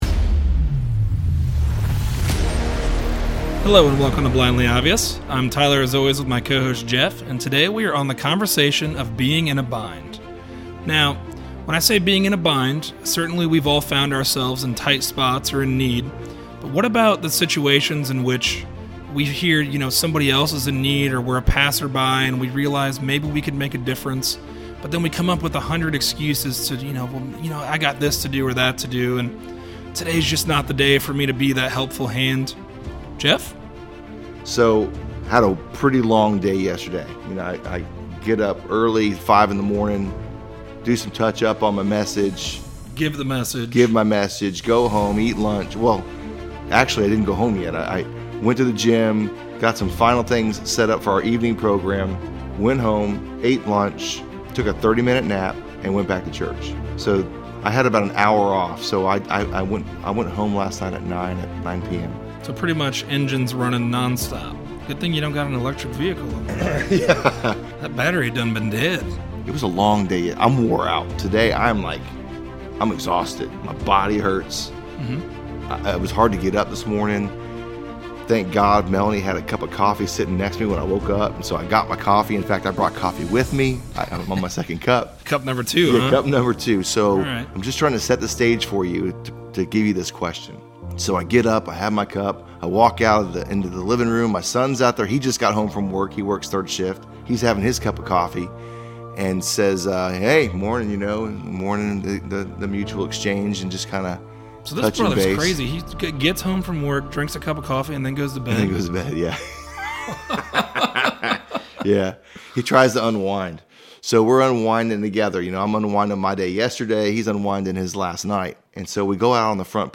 A conversation on being in a bind. When we are called on to be a helping hand, are we always sure we’ll do the right things, even if what is right doesn’t pertain to us or even inconveniences us?